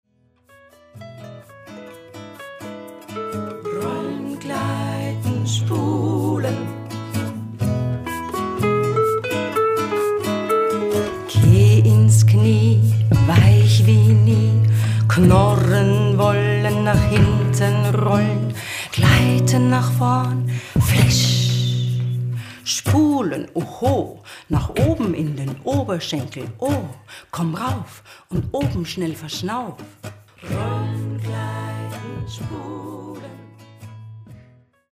vocals, guitar, percussion, ocarina
Recorded at: Kinderzimmer Studios